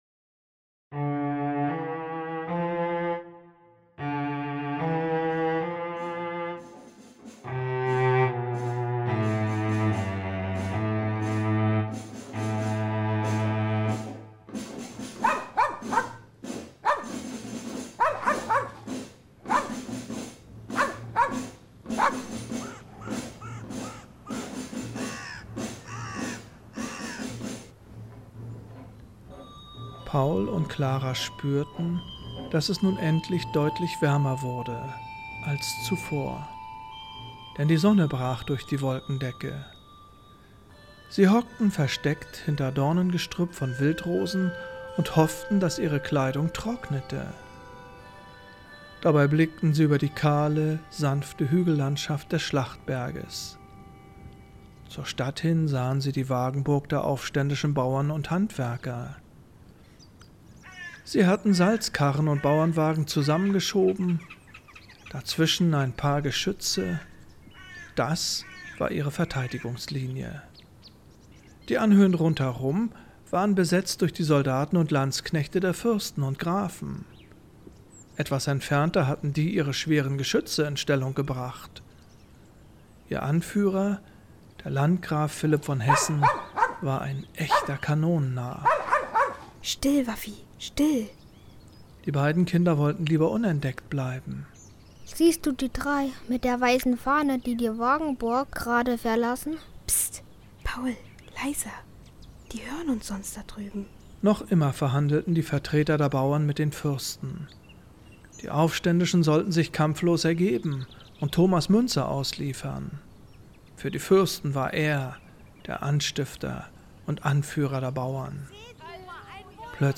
Hörprobe - Hörspiel Panorama Museum Bad Frankenhausen
Ende November begann die Hörspielproduktion mittels eines virtuellen Tonstudios. Dazu wurden zwei Großmembranmikrophone und eine improvisierte Sprechkabine benötigt.